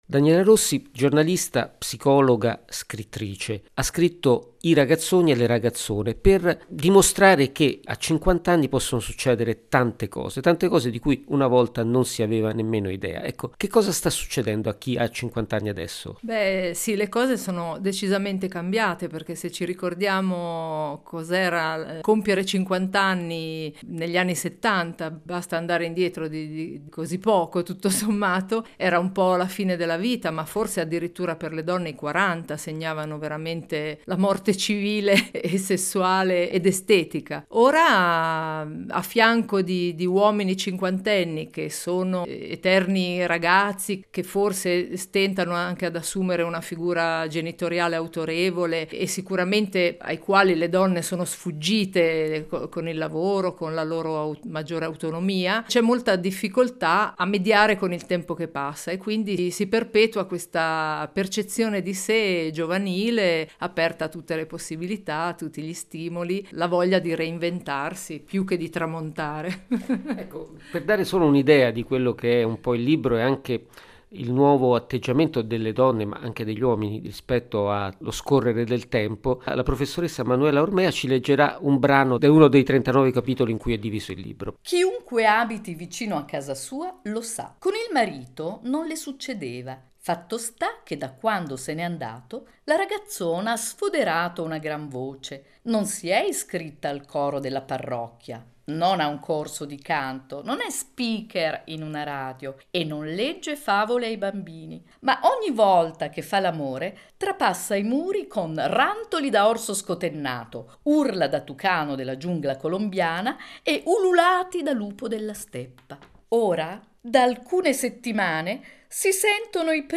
Intervista – Libro “Le Ragazzone”
Intervista-LeRagazzone.mp3